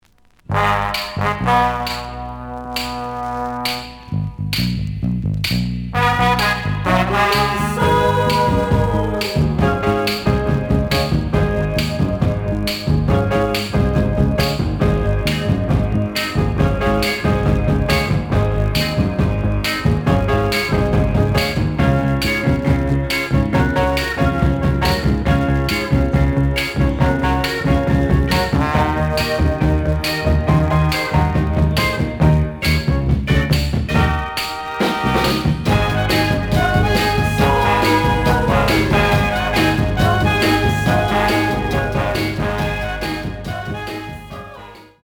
The audio sample is recorded from the actual item.
●Genre: Soul, 60's Soul
Slight noise on B side.)